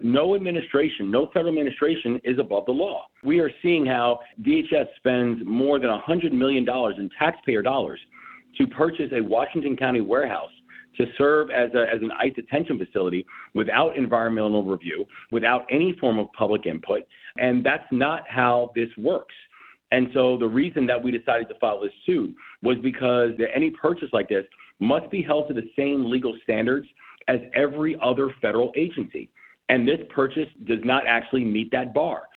Gov. Moore on why Maryland filed a lawsuit against DHS on planned ICE facility